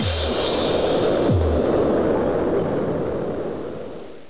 Round music